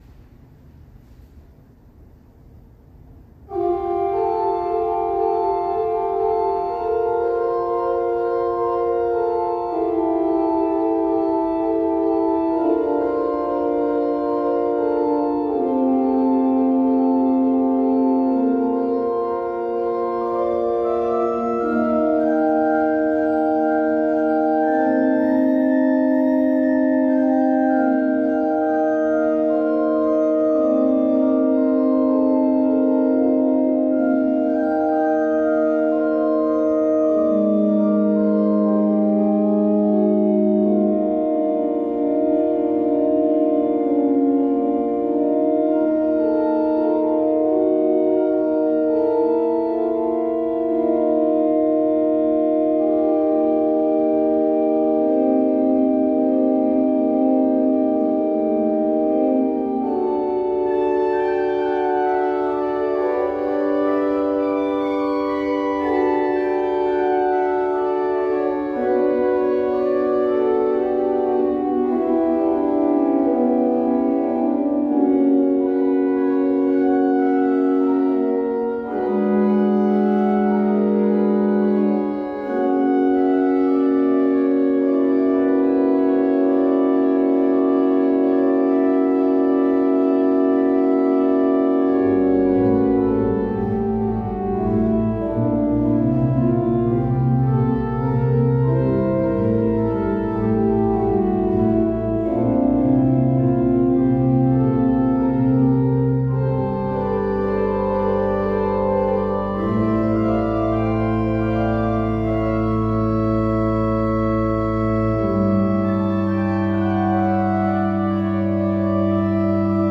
Komposition für Organisten mit verletztem rechten Bein II